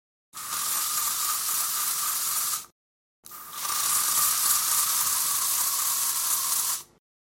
Звуки дезодоранта
Звук: наносим аэрозоль на кожу тела